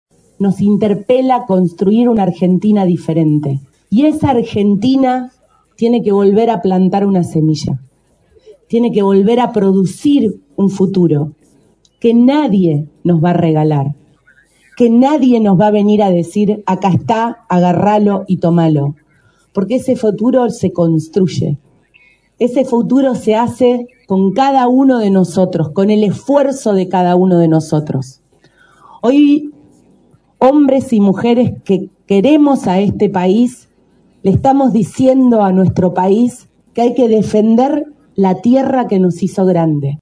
La capital provincial fue escenario este miércoles del lanzamiento oficial de la campaña de Provincias Unidas en Santa Fe, el frente político que reúne a seis gobernadores del interior del país y que busca consolidarse en las elecciones legislativas del 26 de octubre como alternativa a la polarización entre el kirchnerismo y el oficialismo de Javier Milei.